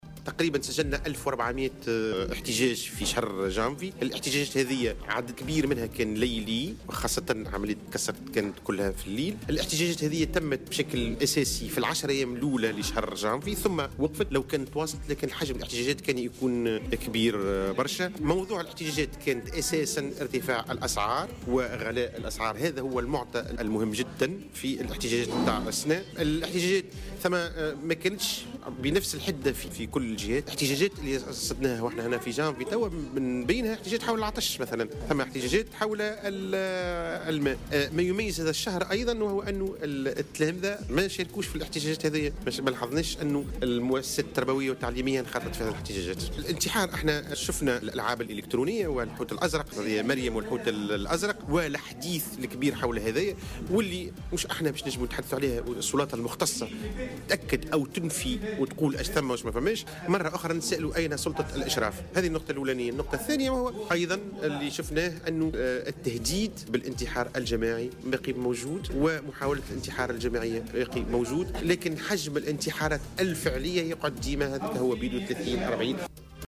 في تصريح لمراسل "الجوهرة أف أم" على هامش ندوة صحفية اليوم